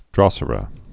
(drŏsər-ə)